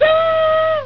Vega-squeal.wav